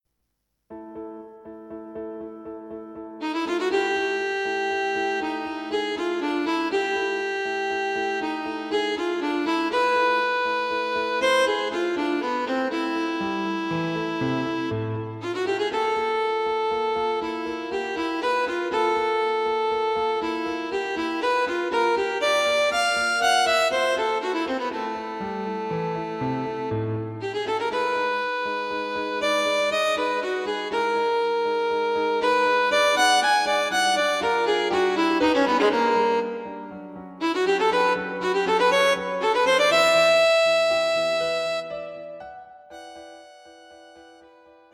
Viola and Piano